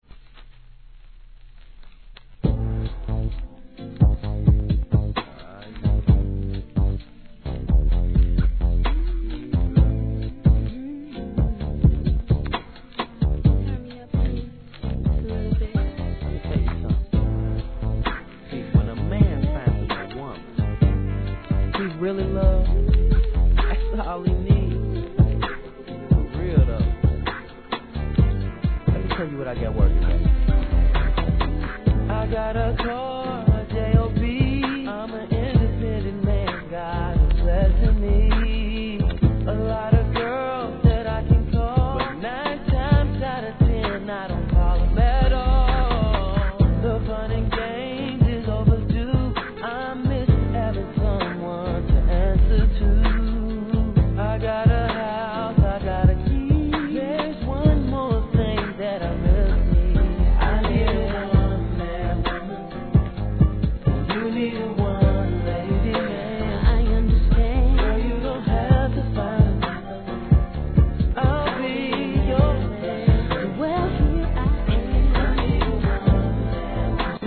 HIP HOP/R&B
全体的にしっとりとしたコーラスのSLOW〜ミディアムで聴かせます♪